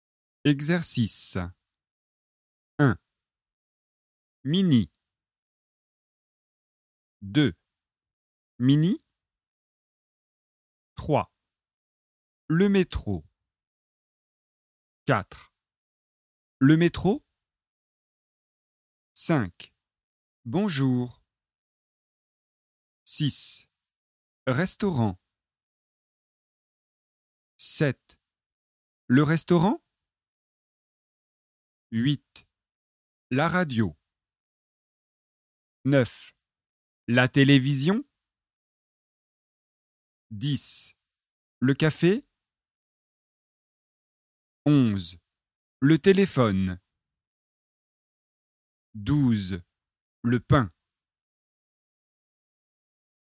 Égalité syllabique - exercice de prononciation
• En français, les syllabes sont régulières.
• Seule la dernière voyelle prononcée est accentuée. Elle est plus longue.
exercice-egalite-syllabique.mp3